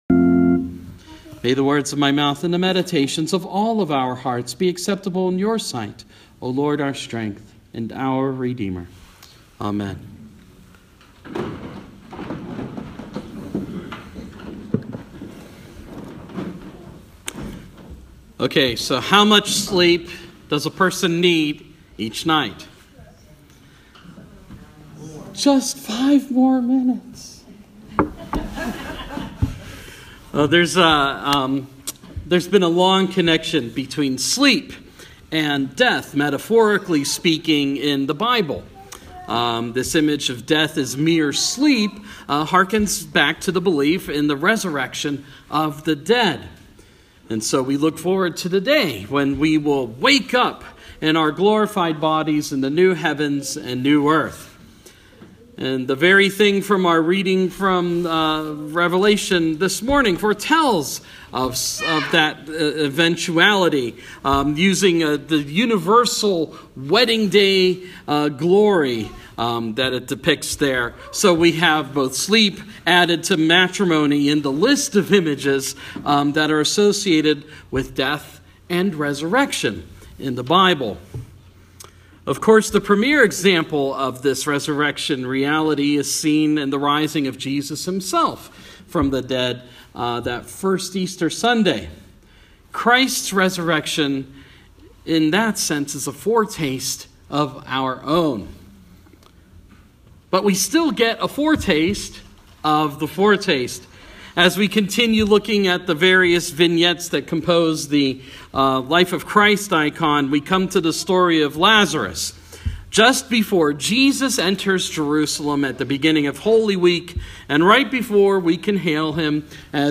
Sermon – Life of Christ – Raising of Lazarus